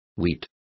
Complete with pronunciation of the translation of wheats.